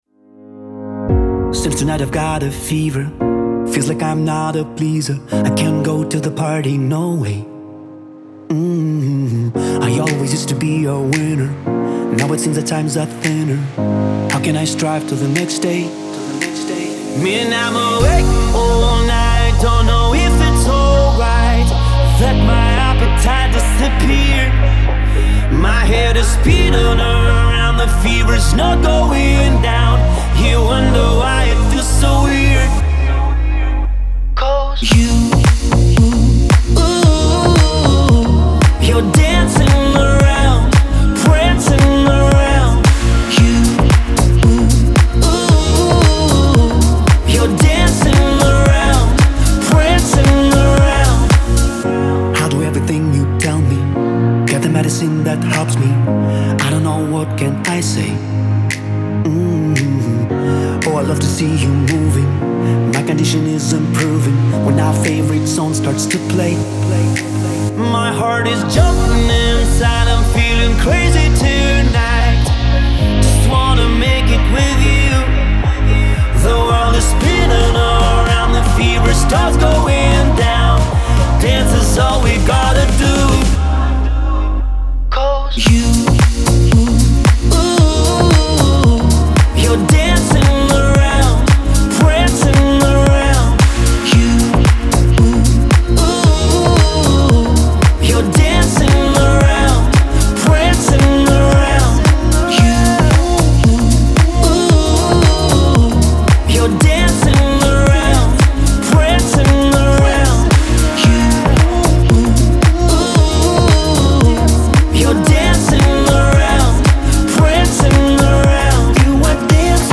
использует свой выразительный вокал